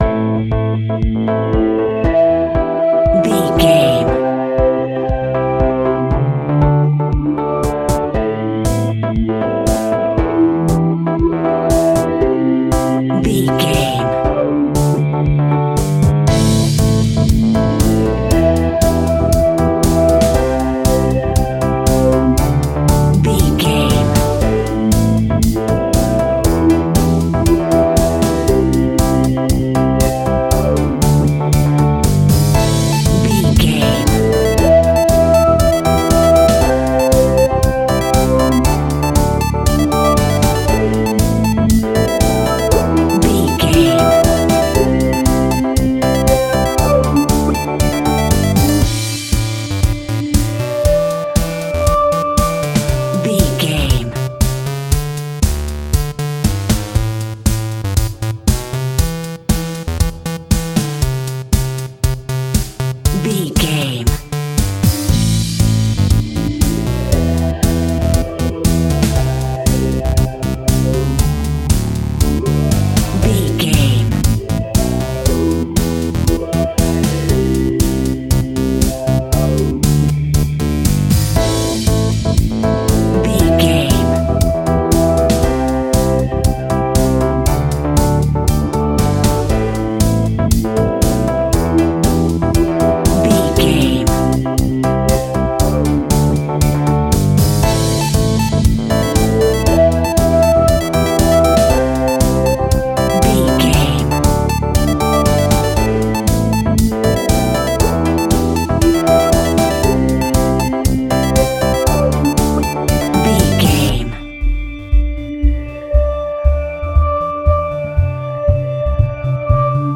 Aeolian/Minor
groovy
driving
energetic
electric piano
synthesiser
drums
bass guitar
deep house
upbeat
electronic drums
synth lead
synth bass